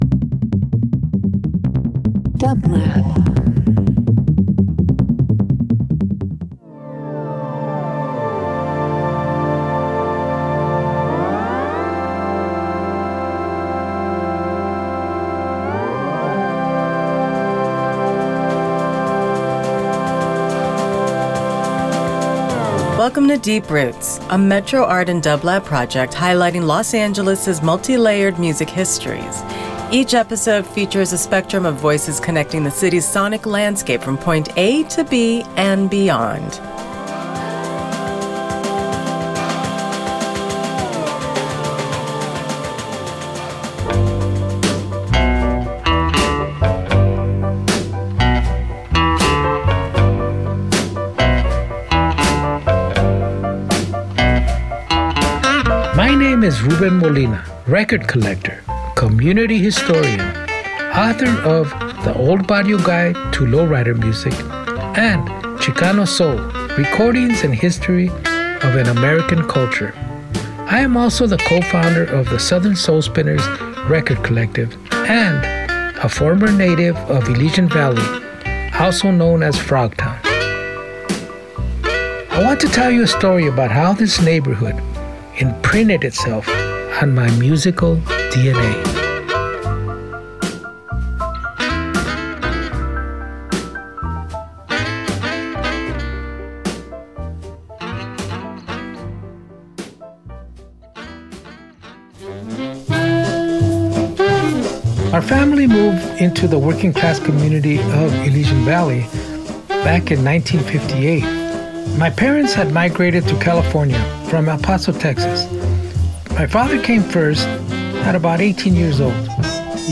Each episode is a multimedia dive into the various intersecting musical histories embedded into the streets, buildings, and neighborhoods of Los Angeles. Hear from a spectrum of musicians who defined their respective scenes along with a collaged soundtrack of hand selected music, interviews, archival photos, and a broadcasted livestream of the accompanying geographies as seen from a Metro Bike as visual accompaniment.